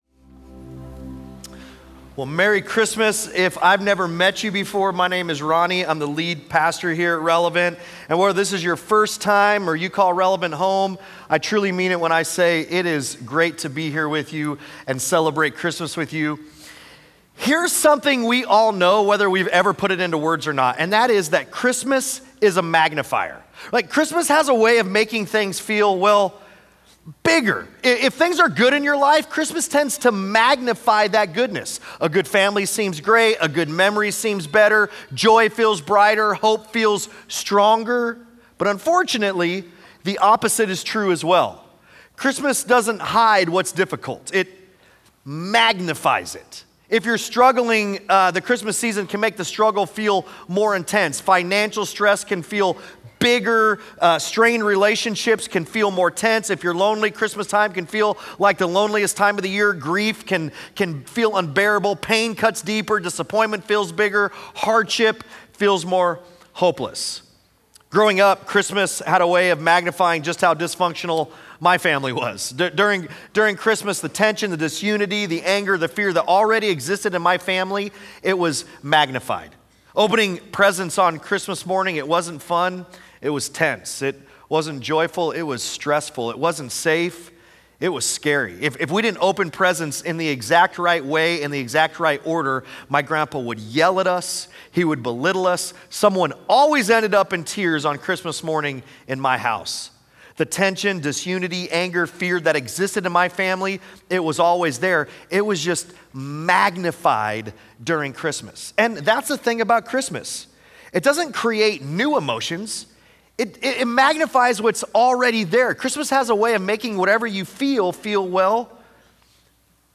Sunday Sermons Christmas with Relevant Dec 24 2025 | 00:30:25 1x 00:00 / 00:30:25 Subscribe Share Apple Podcasts Spotify Overcast RSS Feed Share Link Embed